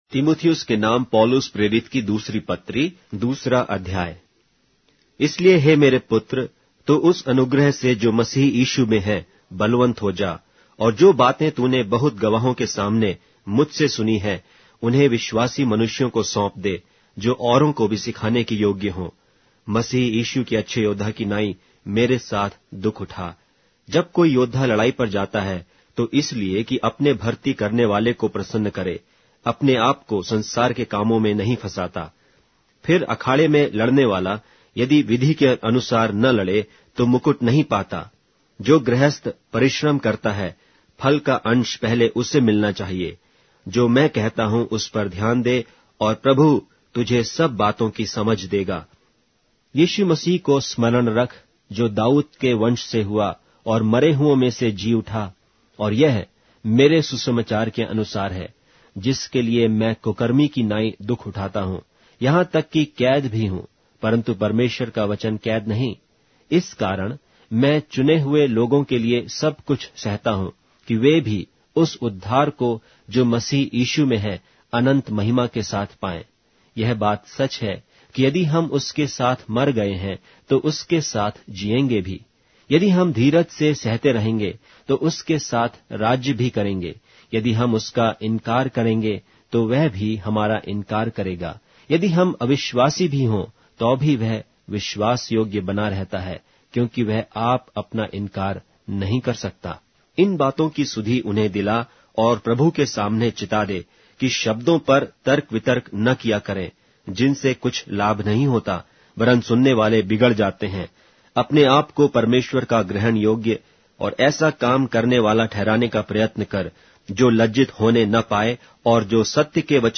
Hindi Audio Bible - 2-Timothy 1 in Mov bible version